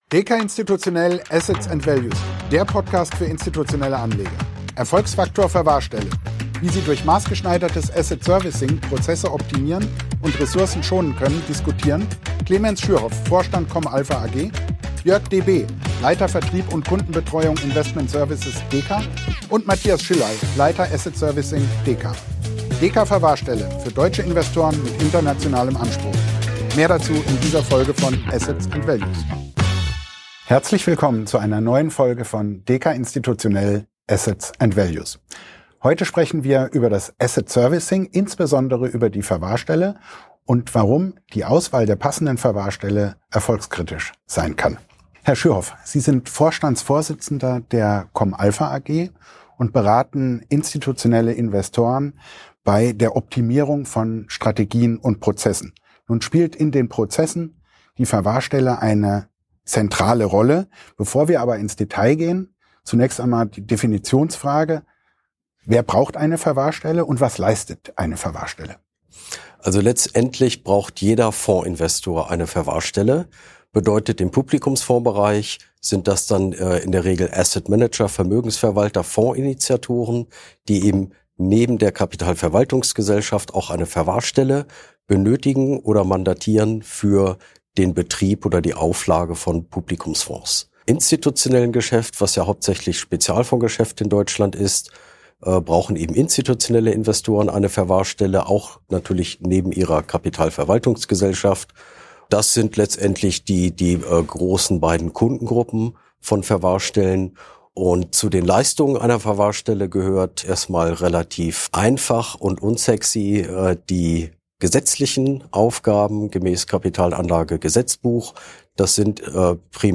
Beschreibung vor 1 Jahr Praktisch jeder Investor braucht sie, praktisch jedes systematisch handelbare Asset wird erfasst: die Verwahrstelle spielt eine zentrale Rolle in den Prozessen von institutionellen Anlegern. Unsere Experten diskutieren über Marktstruktur und teilnehmer, neue Entwicklungen und Trends, Ertrags und Kostensenkungspotenziale durch die Kombination mit einer Master-KVG und natürlich, auf welche Kriterien man bei der Auswahl der passenden Verwahrstelle achten sollte.